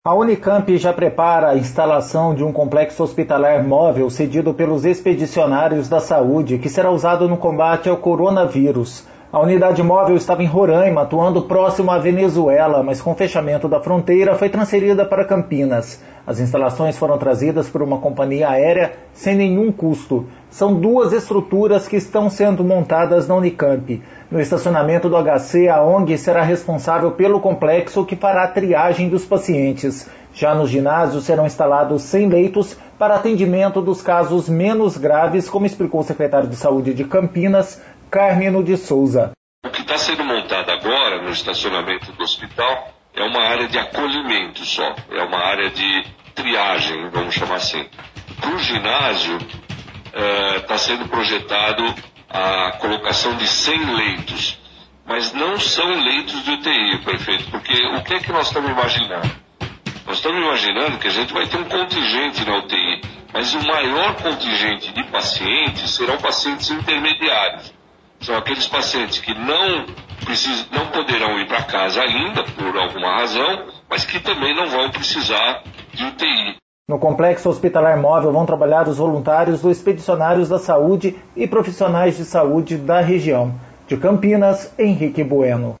Já no ginásio, serão instalados 100 leitos para atendimento dos casos menos graves, como explicou o secretário de saúde de Campinas, Cármino de Sousa.